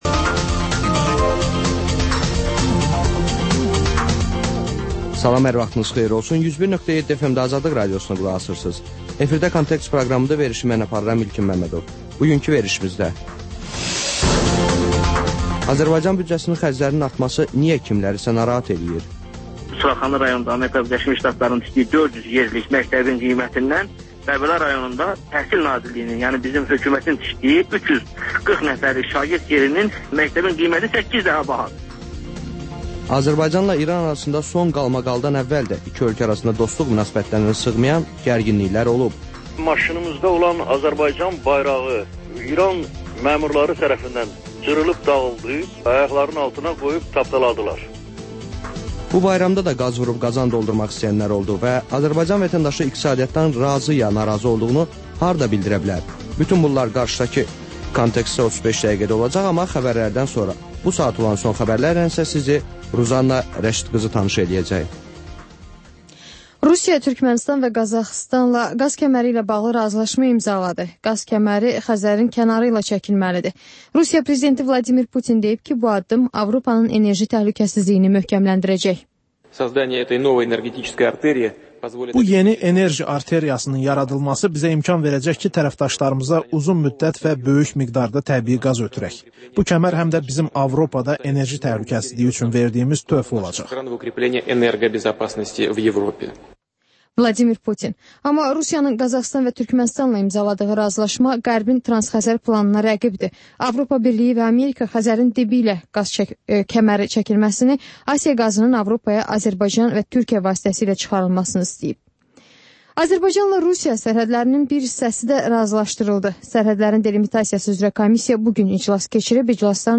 Xəbərlər, müsahibələr, hadisələrin müzakirəsi, təhlillər, sonra QAFQAZ QOVŞAĞI rubrikası: «Azadlıq» Radiosunun Azərbaycan, Ermənistan və Gürcüstan redaksiyalarının müştərək layihəsi